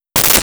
Camera Classic Flash 01
Camera Classic Flash 01.wav